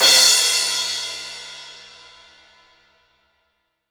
Index of /90_sSampleCDs/AKAI S6000 CD-ROM - Volume 3/Drum_Kit/DRY_KIT2